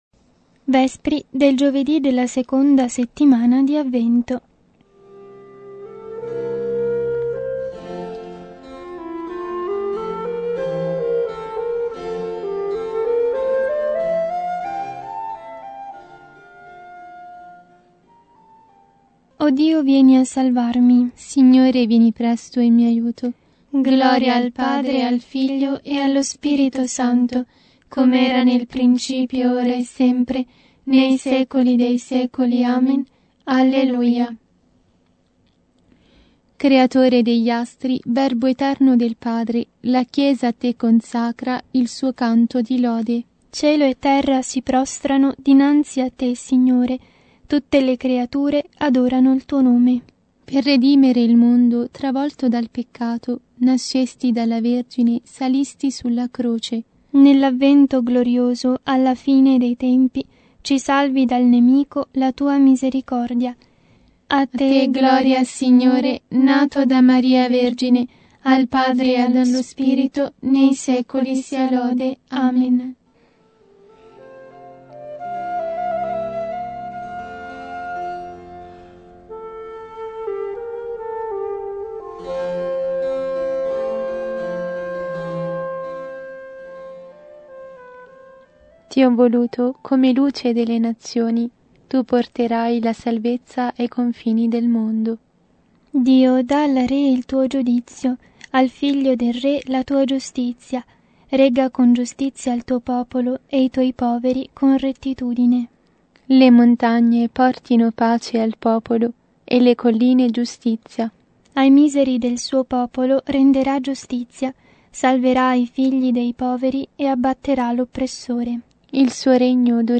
Vespri – Giovedì – della seconda settimana di Avvento